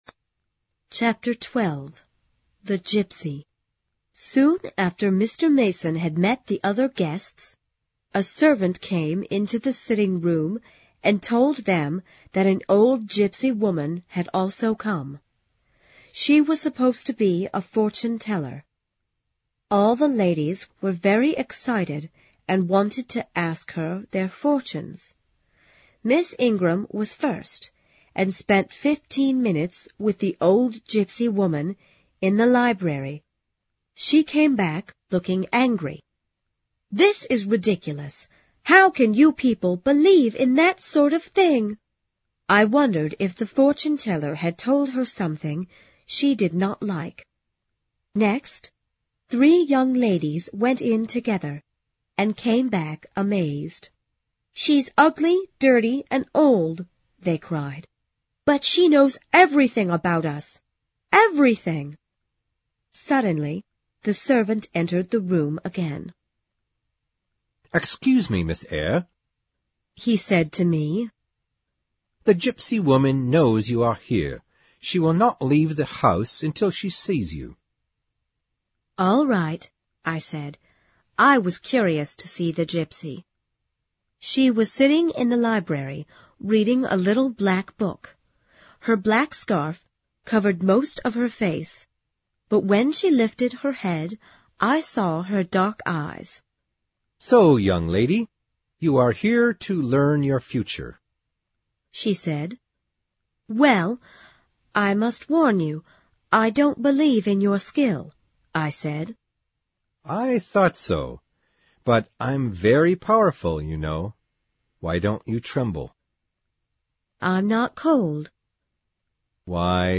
有声名著之简爱Jene Eyer Chapter12 听力文件下载—在线英语听力室